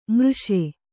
♪ mřṣe